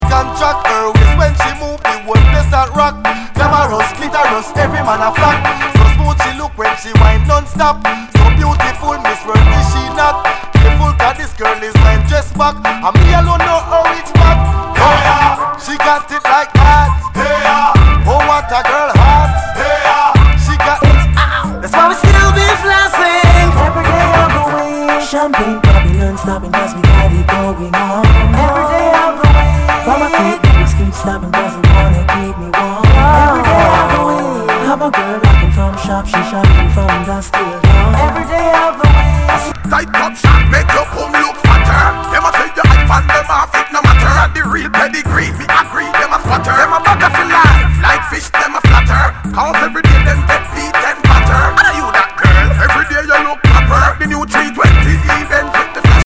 類別 雷鬼
ナイス！ダンスホール！
全体にチリノイズが入ります